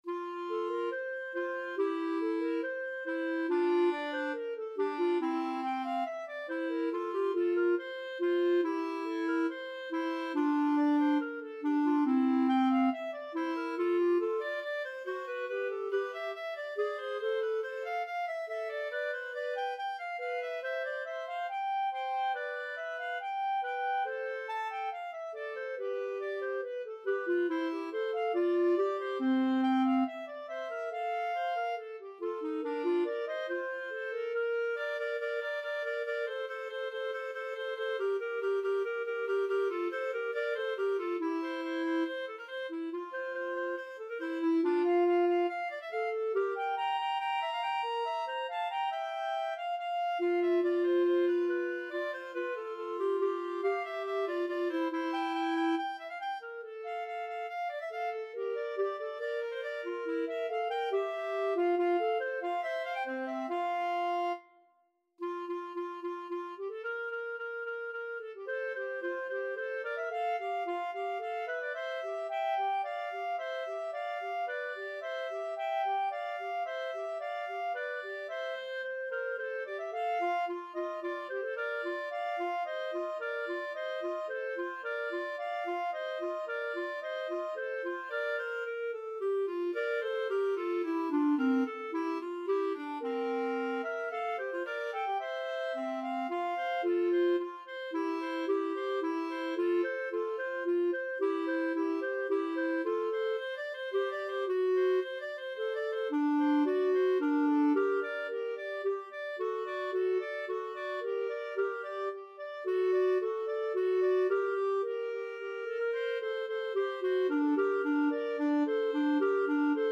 Free Sheet music for Clarinet Duet
F major (Sounding Pitch) G major (Clarinet in Bb) (View more F major Music for Clarinet Duet )
4/4 (View more 4/4 Music)
~ = 70 Affettuoso
Classical (View more Classical Clarinet Duet Music)